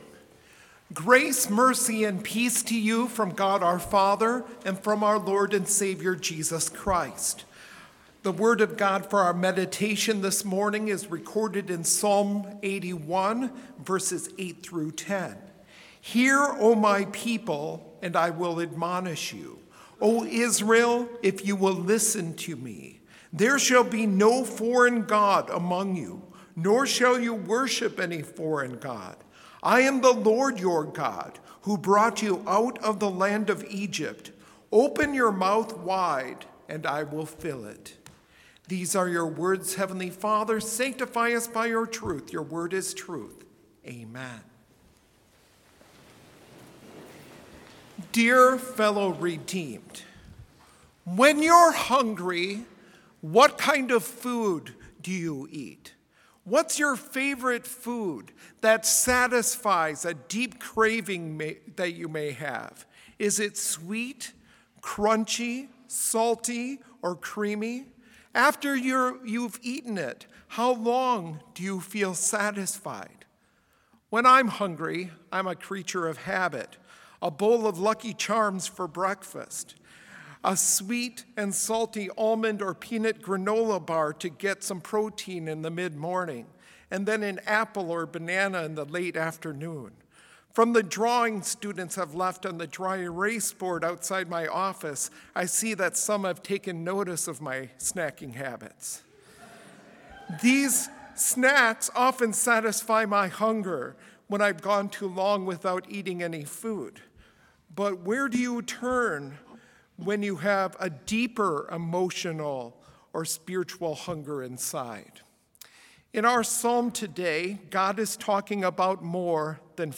Complete service audio for Chapel - April 1, 2022